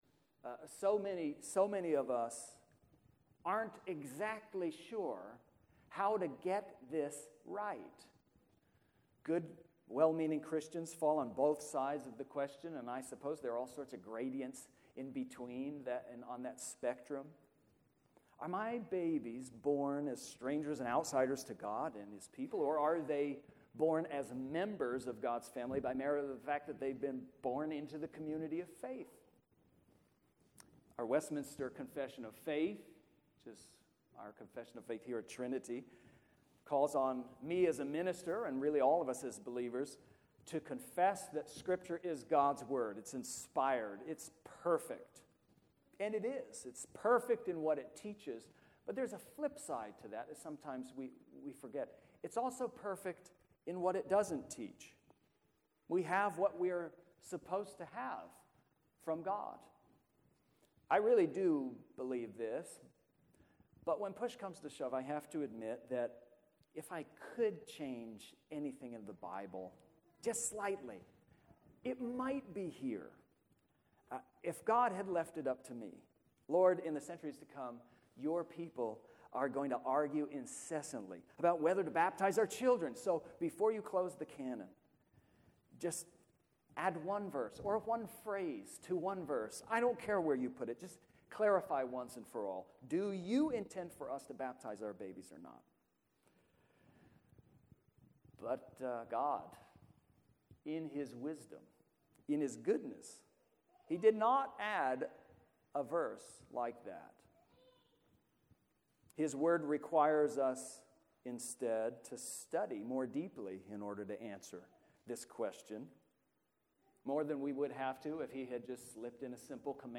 Only the latter half of the sermon was captured on audio.